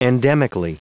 Prononciation du mot endemically en anglais (fichier audio)
Prononciation du mot : endemically